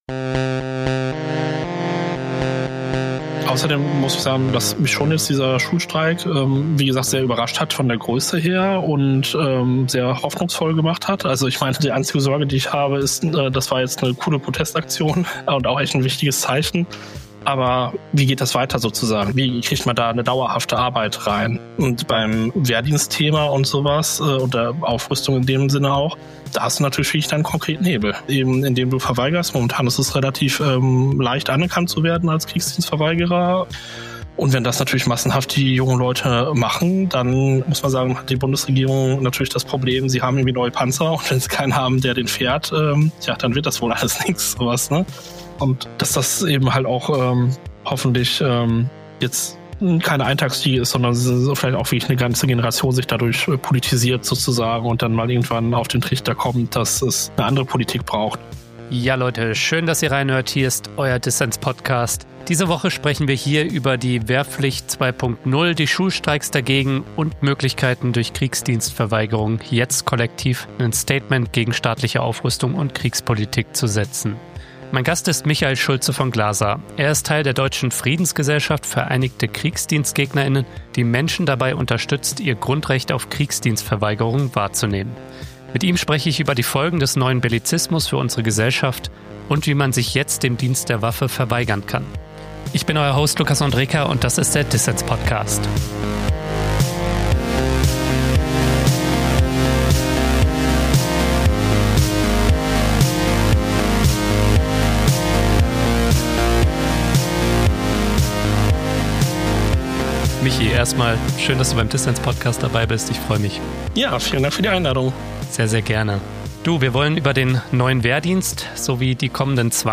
Ein Gespräch über Soldaten im Klassenzimmer, Dos and Dont's beim Verweigern und kollektiven Widerstand gegen die Logik des Militärischen.